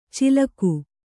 ♪ cilaku